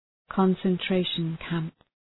concentration-camp.mp3